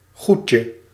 Ääntäminen
Synonyymit stof spul zelfstandigheid substantie Ääntäminen Haettu sana löytyi näillä lähdekielillä: hollanti Käännös Ääninäyte Substantiivit 1. stuff UK US 2. liquid US Suku: m .